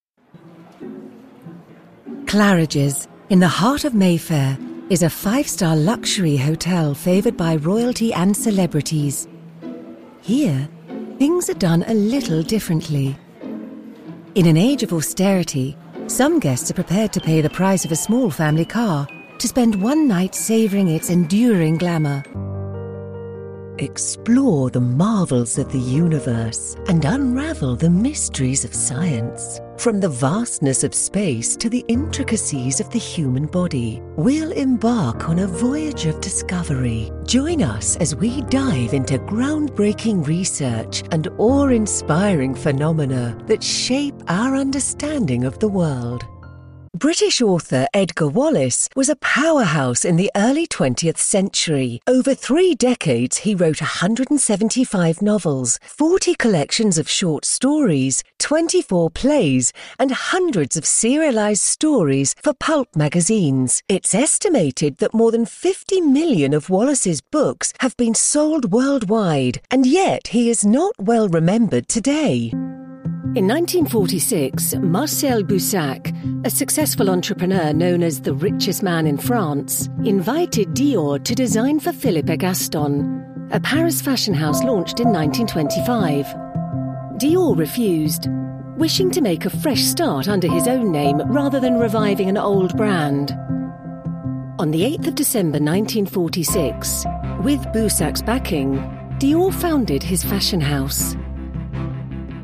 Narration
Velvety, smooth and sophisticated British voice actor… with a multitude of character voices!
Purpose built, isolated and acoustically treated vocal booth, Neumann 103 mic, Scarlett 2i2 Interface and Adobe Audition.
ArticulateEngagingWarm